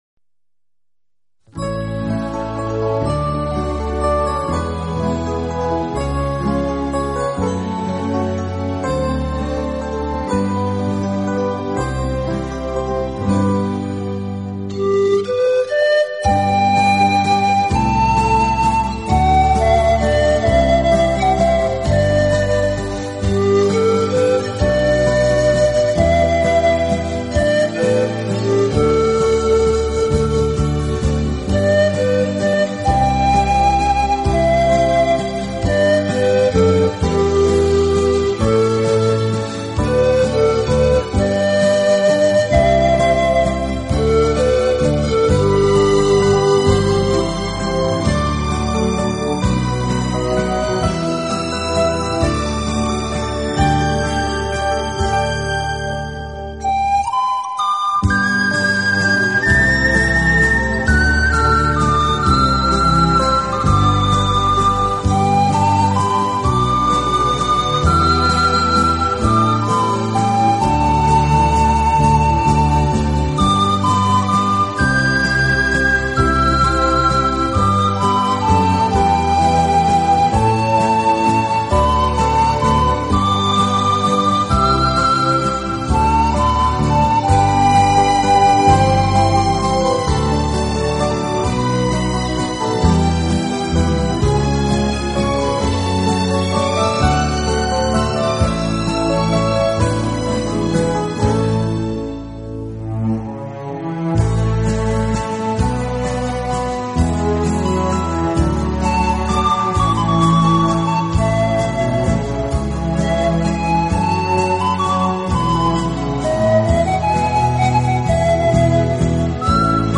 这又是一张非常经典的老曲目经过改编用排箫重新演绎的专辑。